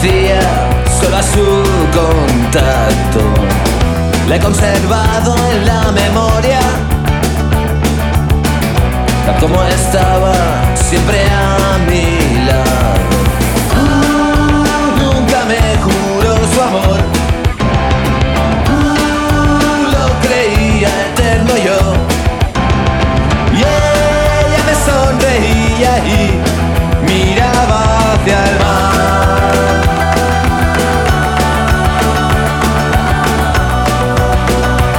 # Alternative and Latin Rock